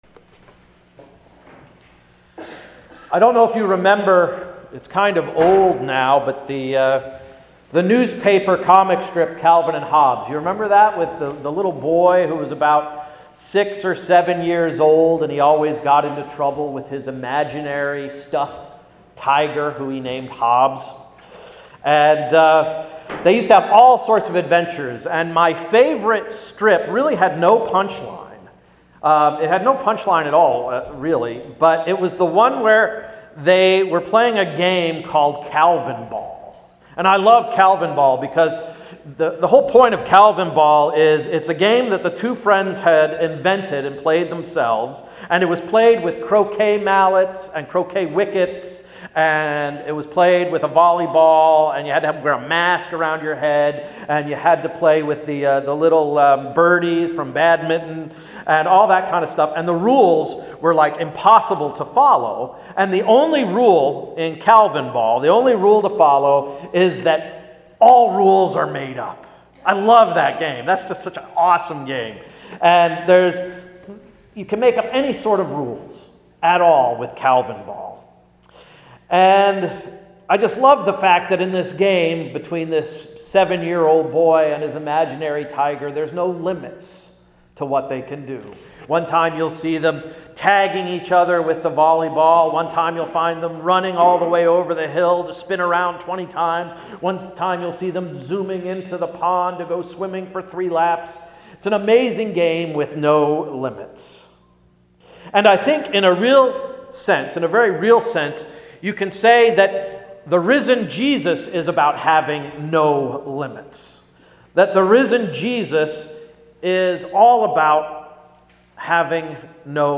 March 27, 2016-Easter Sermon–“No Limits”